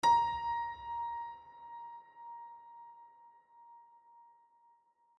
piano-sounds-dev